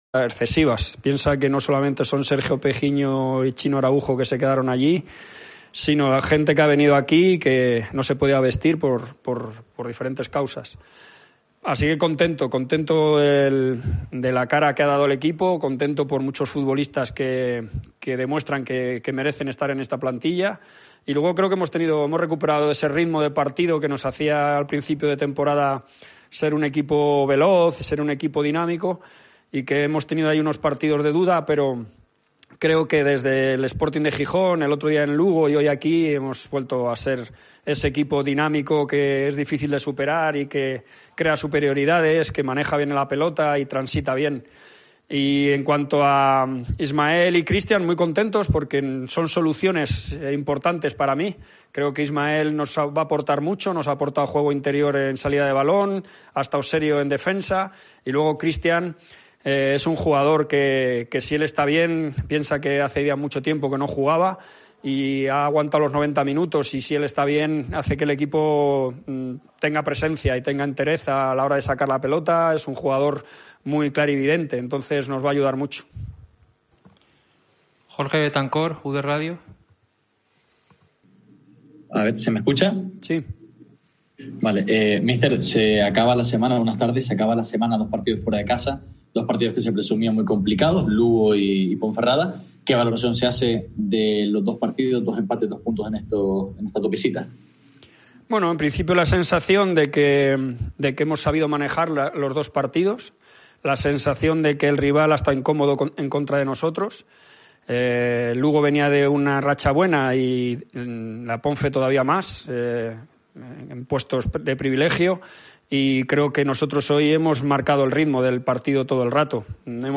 AUDIO: Escucha aquí las declaraciones del míster del Las Palmas, Pepe Mel, y del entrenador de la Deportiva, Jon Pérez Bolo